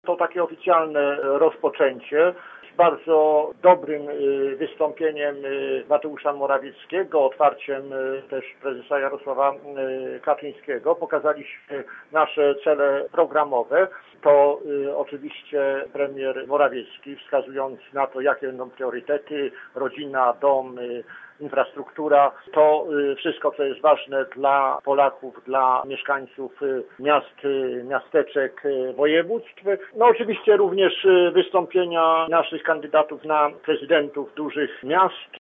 Marek Ast, szef lubuskich struktur Prawa i Sprawiedliwości, uczestnik konwencji powiedział, że to oficjalny początek kampanii wyborczej jego partii.